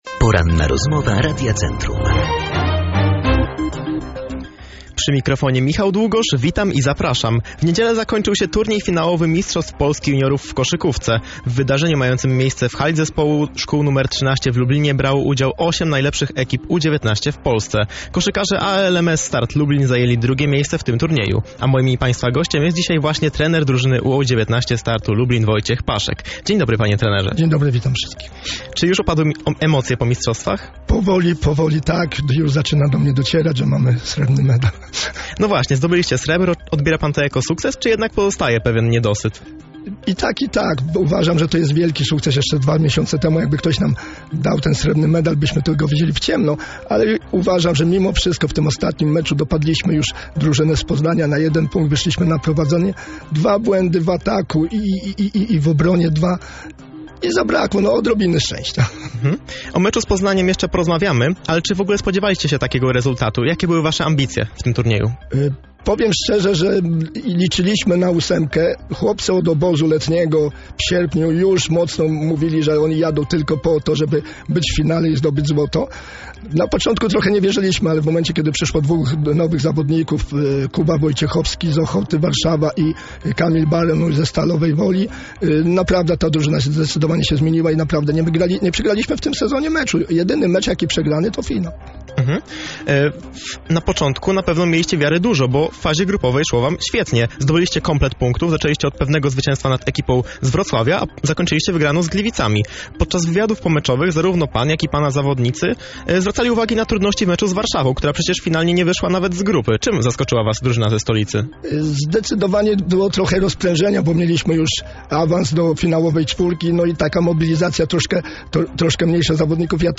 Po Mistrzostwach młodych koszykarzy czekają kolejne wyzwania, o których porozmawialiśmy na antenie naszego radia.